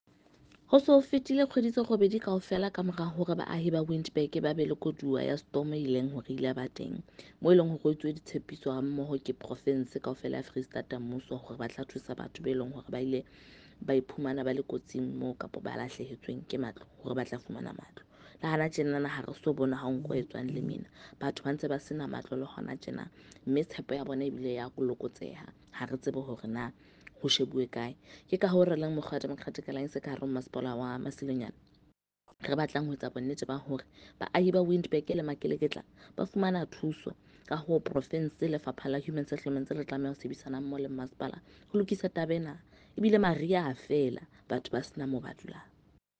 Sesotho by Karabo Khakhau MP.
Sotho-voice-Karabo-3.mp3